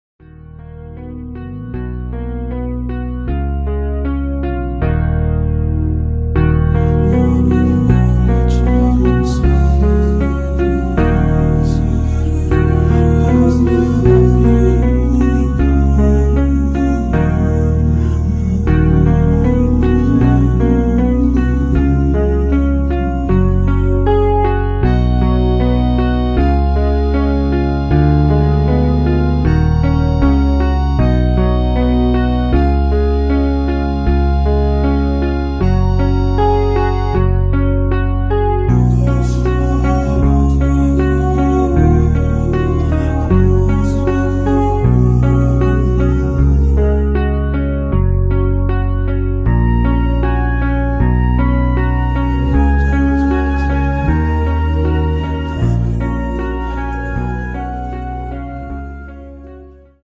F#
◈ 곡명 옆 (-1)은 반음 내림, (+1)은 반음 올림 입니다.
앞부분30초, 뒷부분30초씩 편집해서 올려 드리고 있습니다.
중간에 음이 끈어지고 다시 나오는 이유는